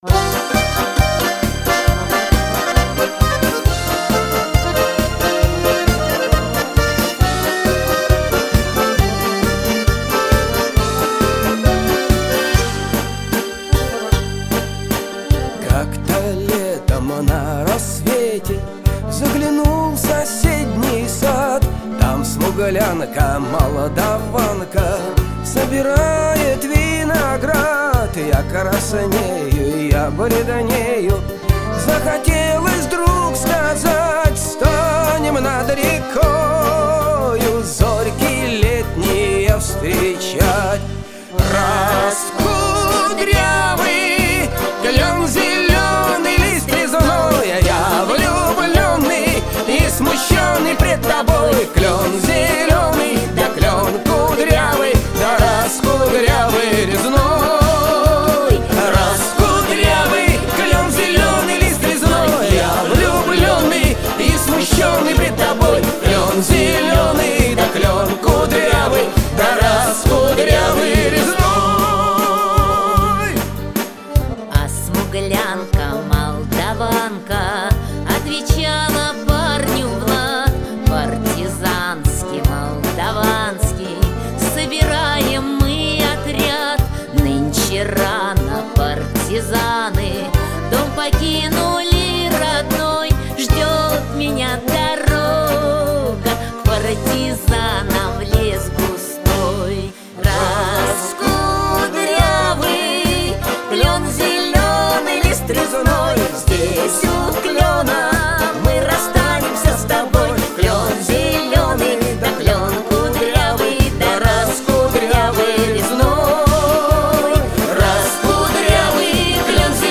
Категория: Военные песни